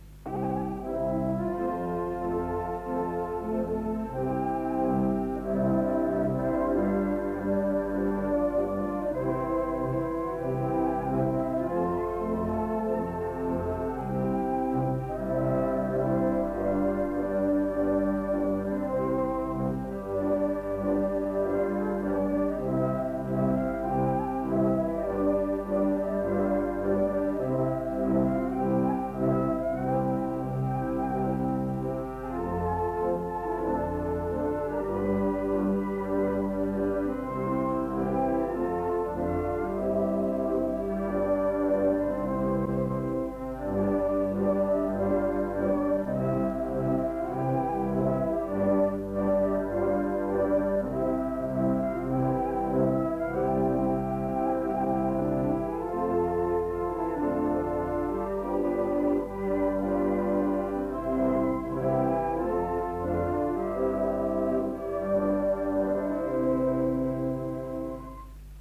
Instrumentalny 15 (Gdy się Chrystus rodzi) – Żeńska Kapela Ludowa Zagłębianki
Nagranie archiwalne